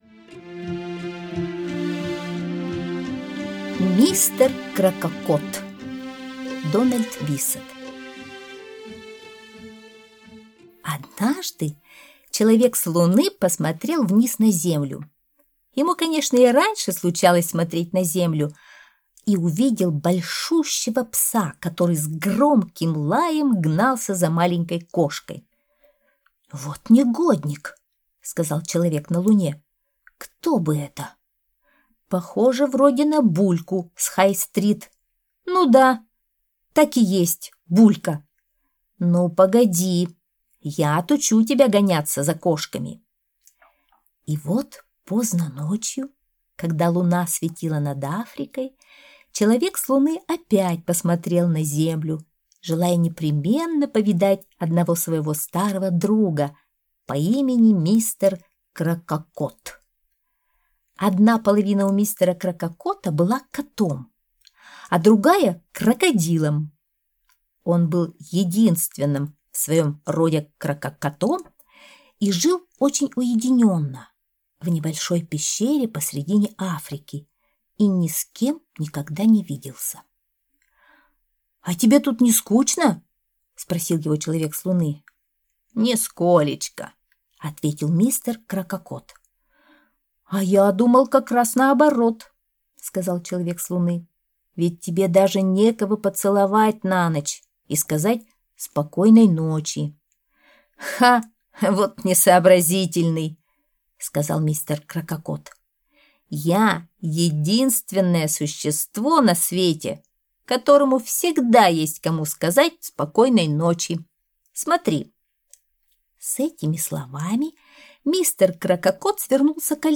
Аудиосказка «Мистер Крококот»